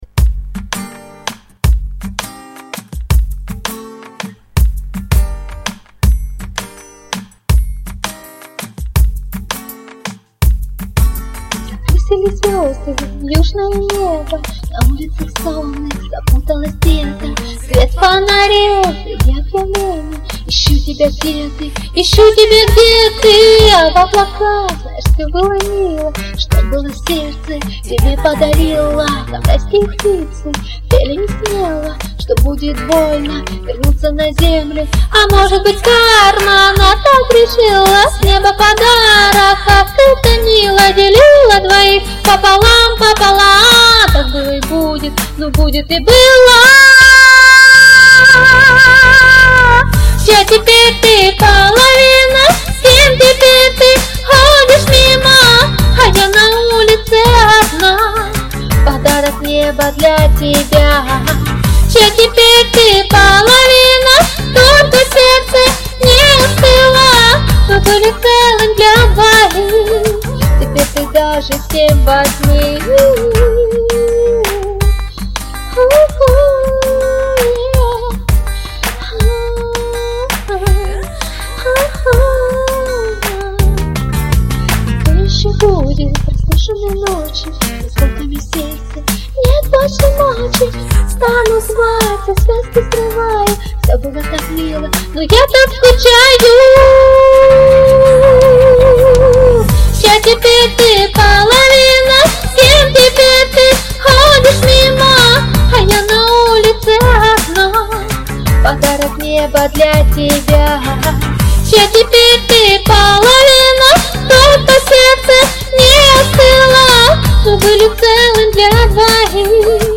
Тем более что у уменя тут запись не очень..